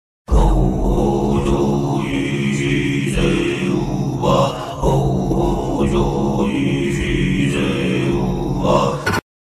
👻 ประตูนรกเปิด 30 วัน! Ghost sound effects free download